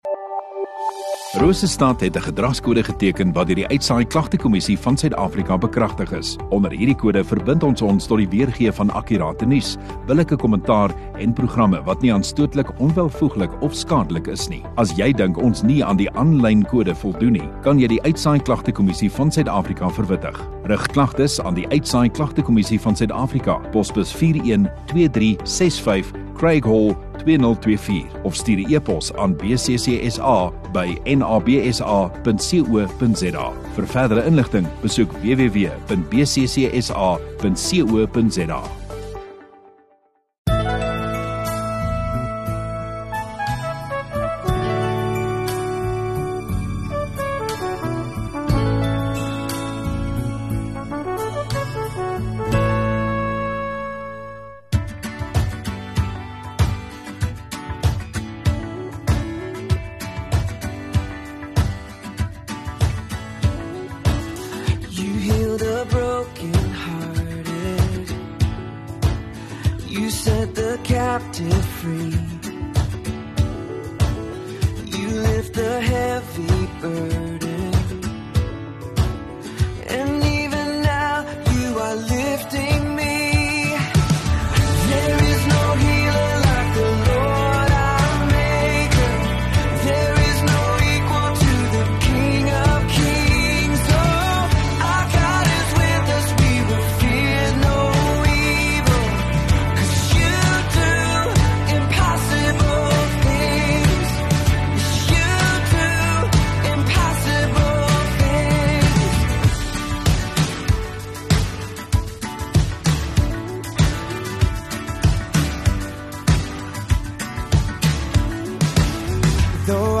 21 Apr Sondagoggend Erediens